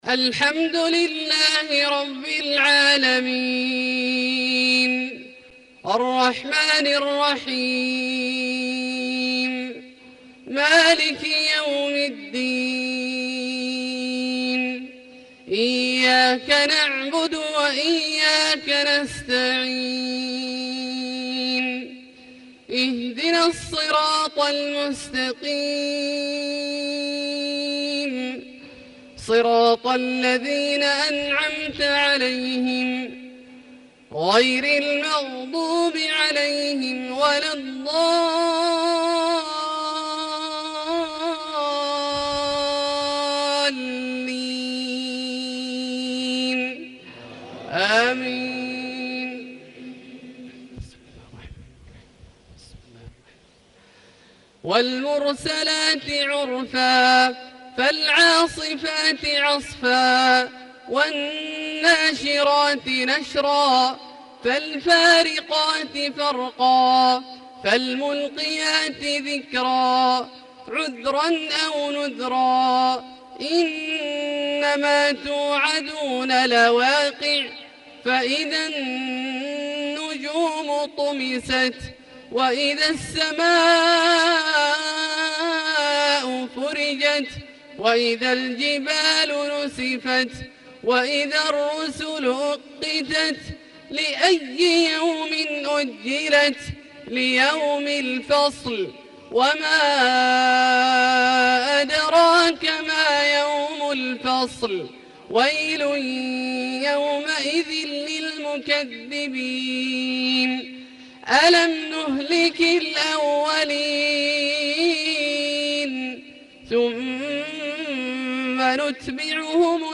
Maghrib prayer Surat Al-Mursalaat 24/3/2017 > 1438 H > Prayers - Abdullah Al-Juhani Recitations